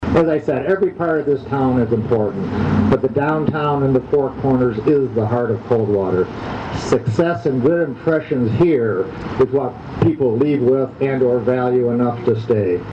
COLDWATER, MI (WTVB) – The renovated Four Corners Parks was formally re-dedicated on September 17 during a short program which included a ribbing cutting.
Coldwater Mayor Tom Kramer said it was a special day.